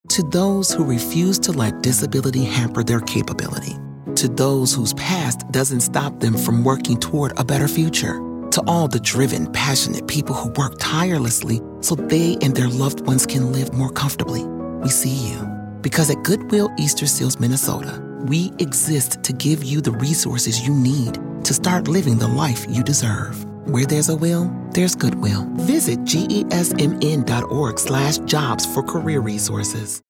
30 Second Audio Spot